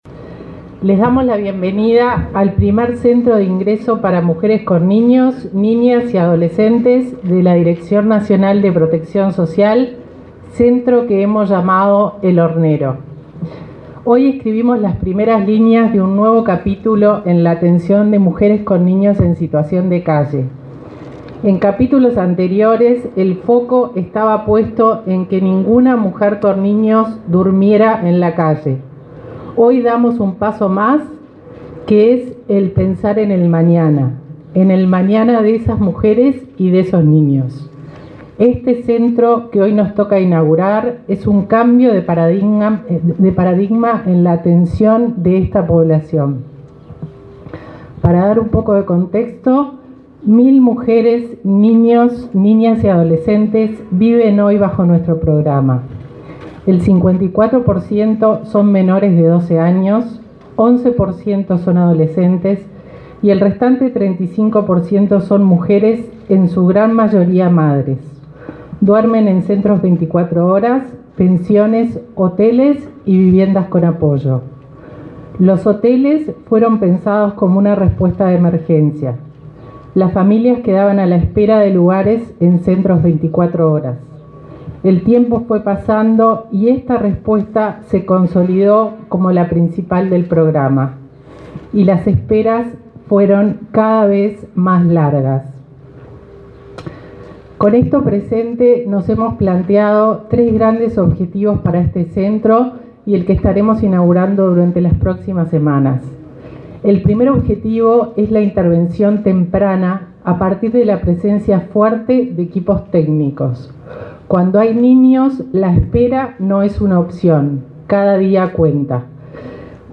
Declaraciones del ministro de Desarrollo Social y la directora nacional de Protección Social